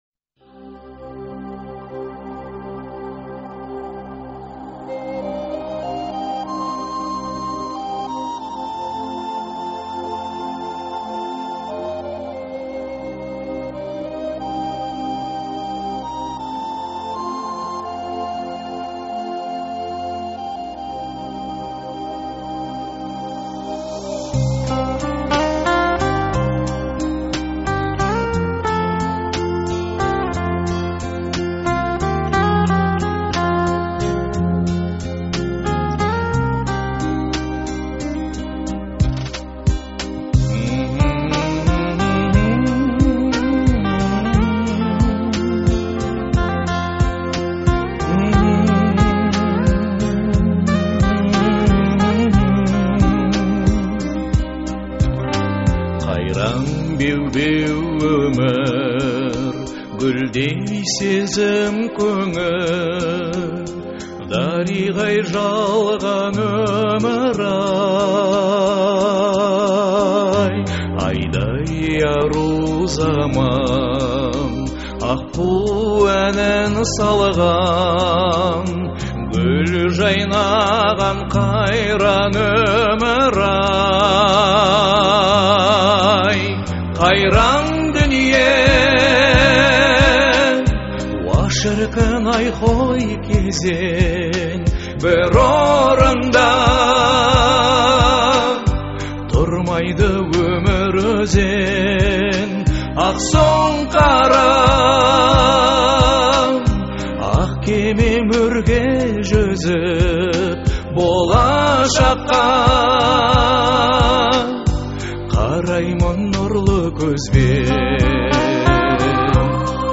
который сочетает в себе элементы поп и фолк музыки.